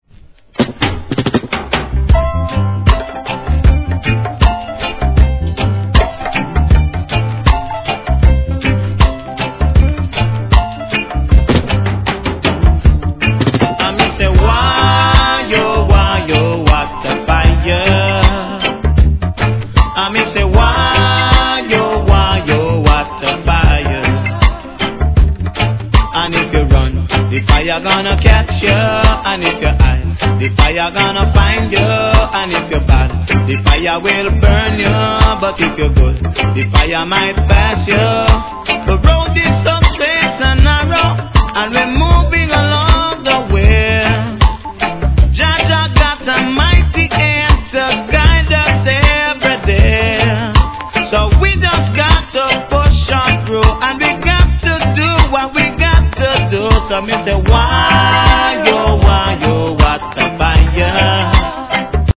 REGGAE
後半DUB接続もイイ感じです!